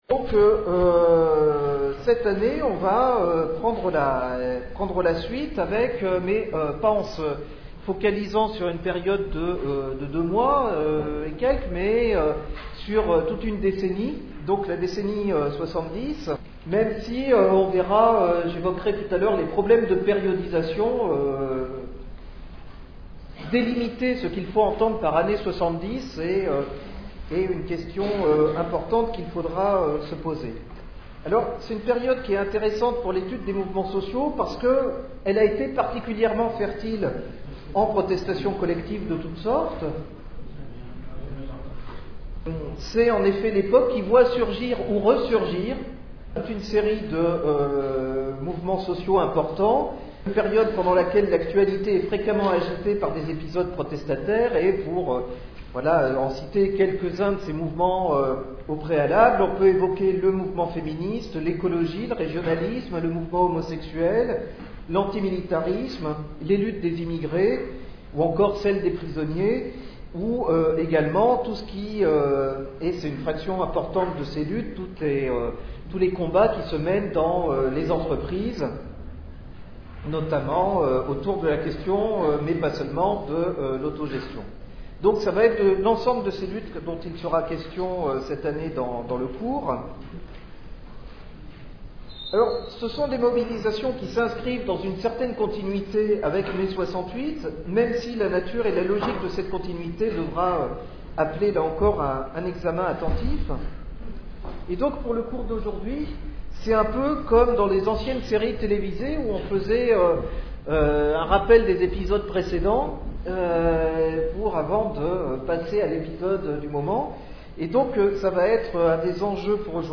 Le cours